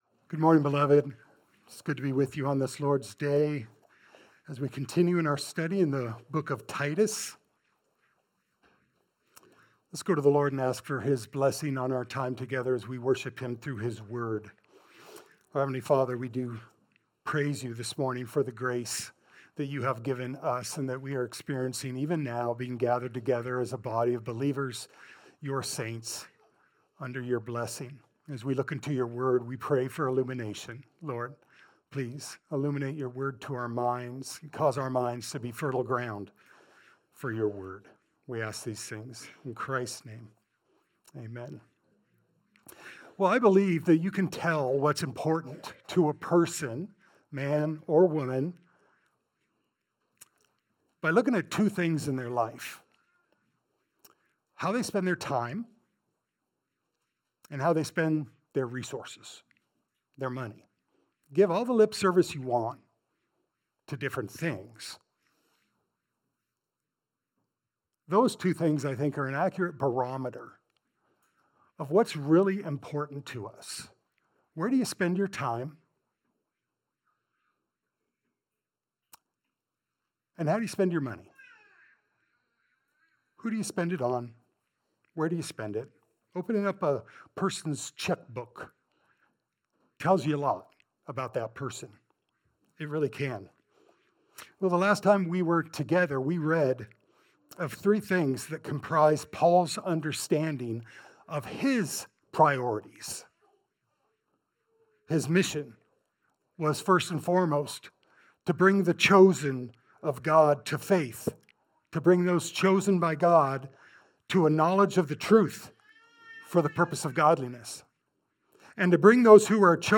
Passage: Titus 1:1-4 Service Type: Sunday Service